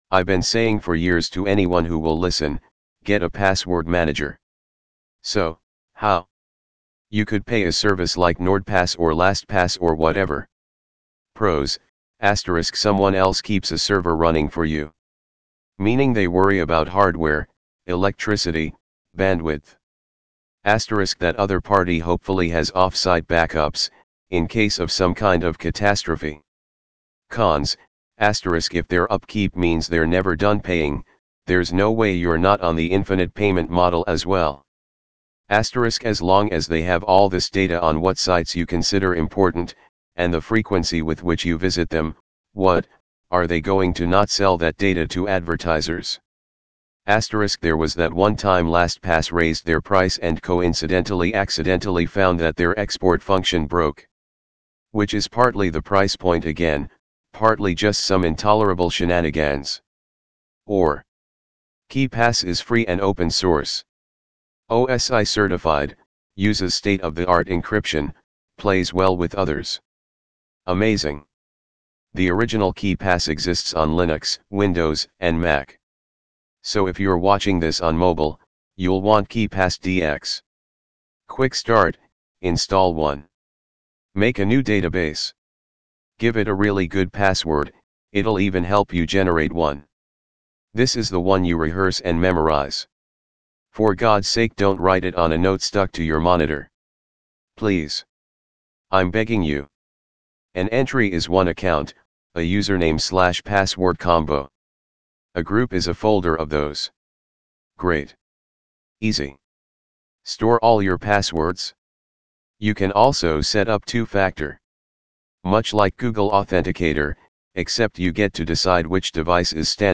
primaryVO.wav